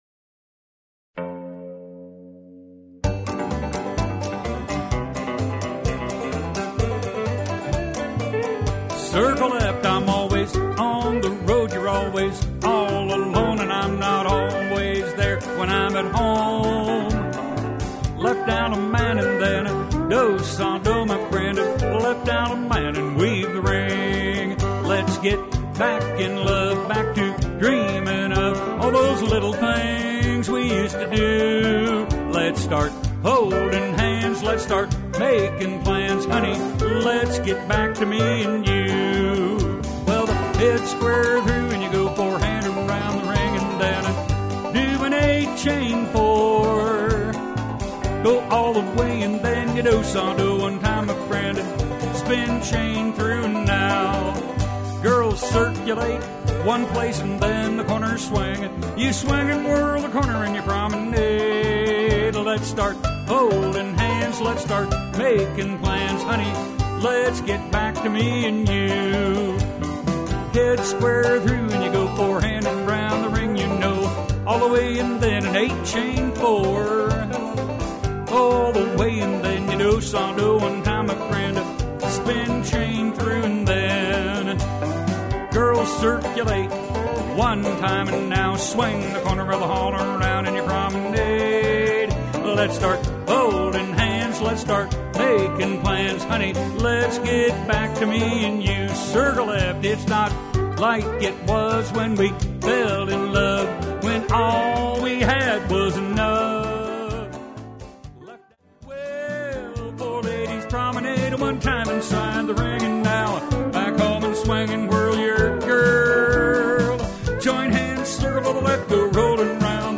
SC = Singing Call